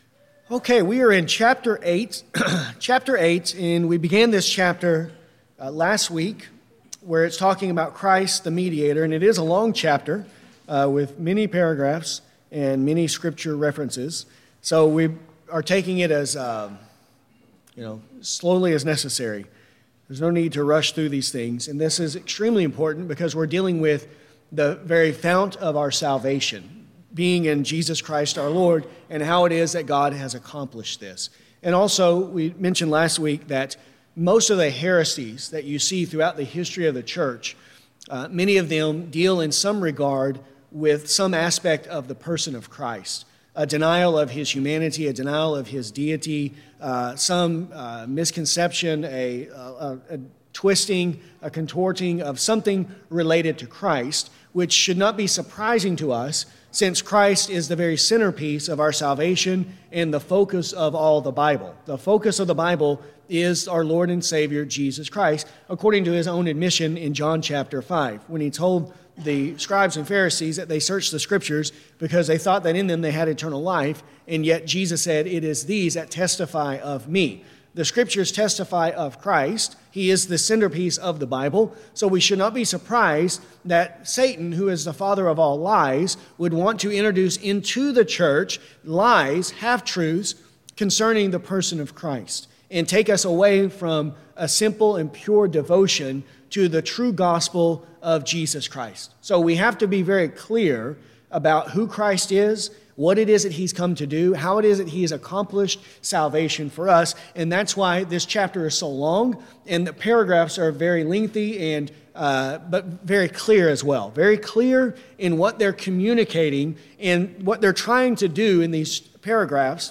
This lesson covers Paragraph 8.2. To follow along while listening, use the link below to view a copy of the confession.